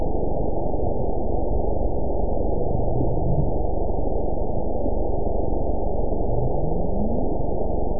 event 922595 date 02/06/25 time 06:44:57 GMT (4 months, 1 week ago) score 8.77 location TSS-AB02 detected by nrw target species NRW annotations +NRW Spectrogram: Frequency (kHz) vs. Time (s) audio not available .wav